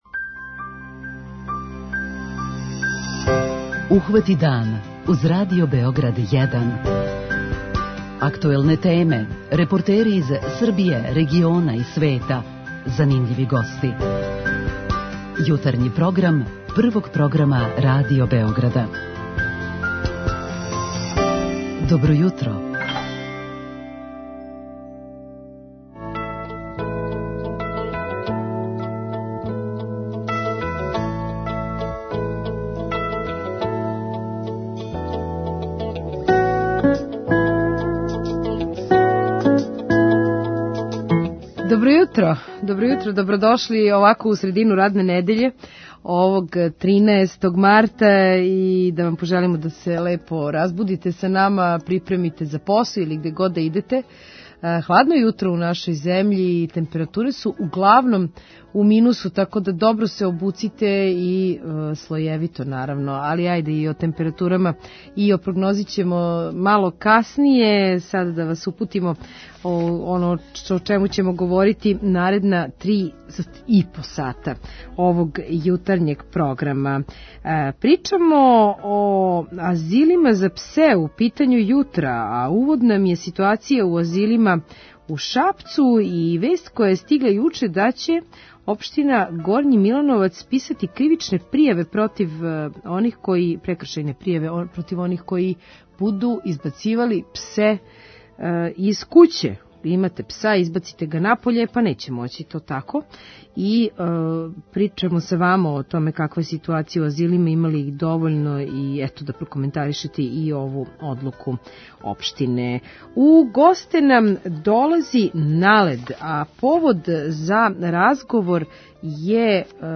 Најављујемо ову манифестацију, а из архиве слушамо и како Мика Антић рецитује своје стихове.